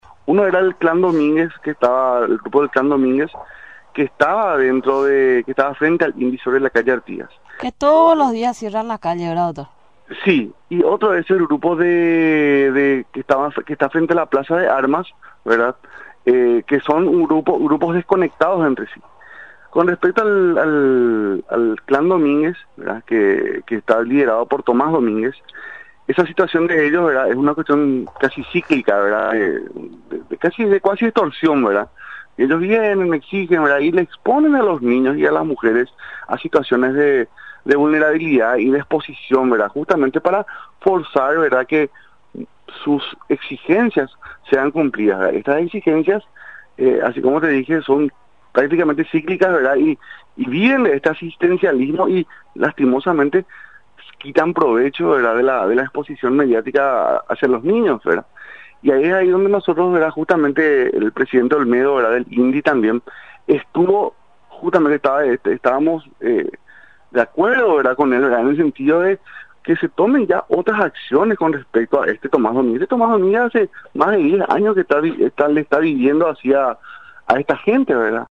El Ministerio de la Niñez y Adolescencia rescata a niños y adolescentes apostados en las plazas de la capital ante las bajas temperaturas, informó el viceministro de Protección Integral de Derechos, Eduardo Escobar, en contacto con Radio Nacional del Paraguay.